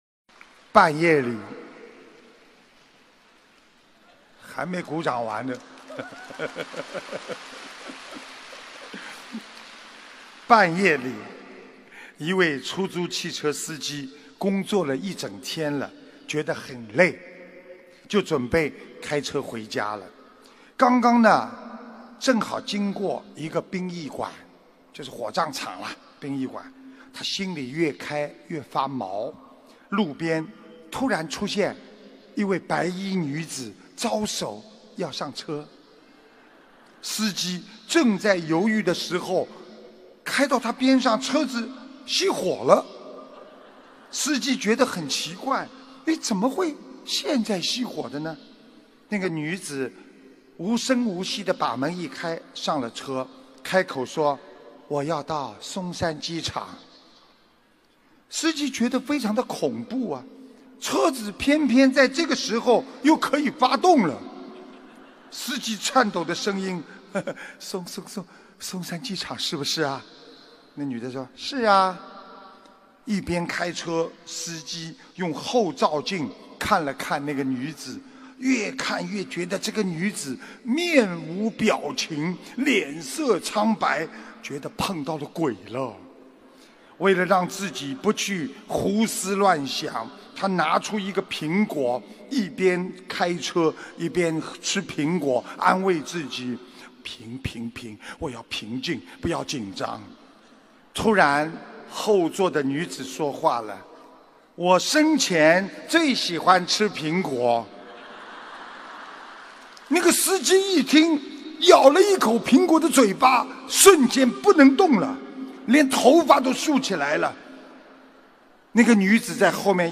·师父讲笑话